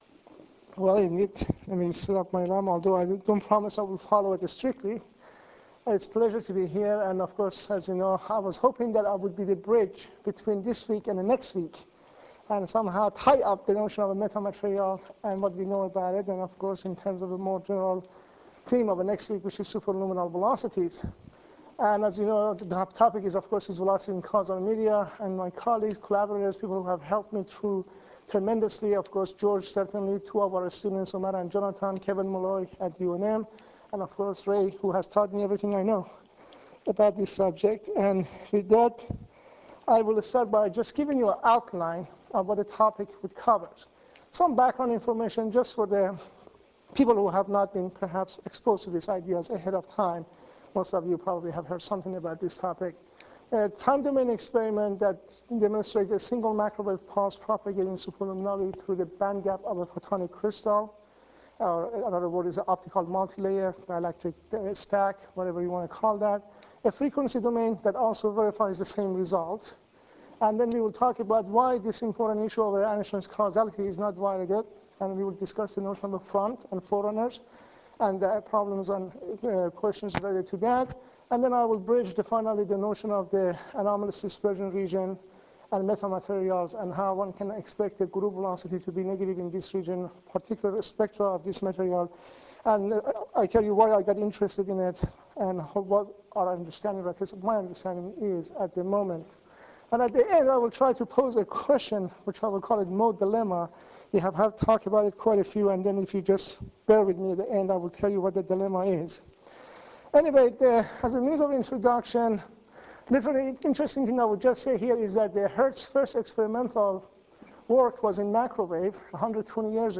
at the Kavli Institute for Theoretical Physics on the Santa Barbara campus of the University of California
WebCam and audio for the whole talk : high bandwidth or medium bandwidth